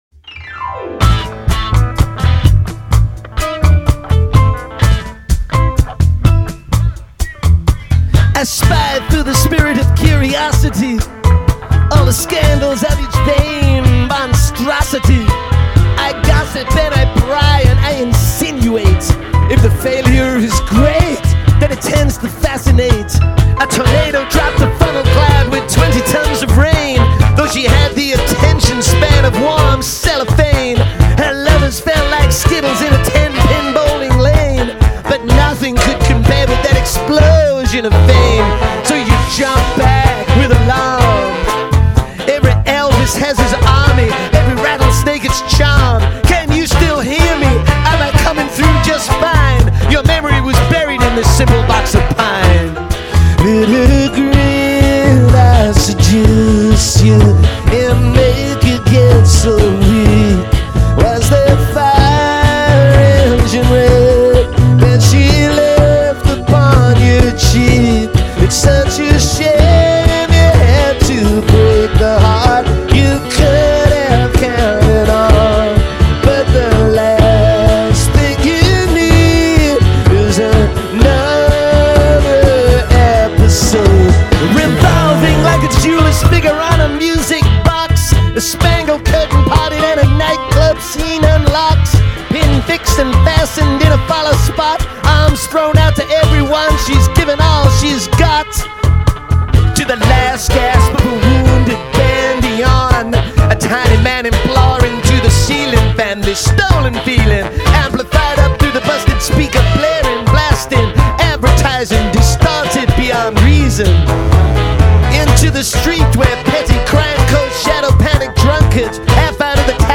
spoken-word diatribe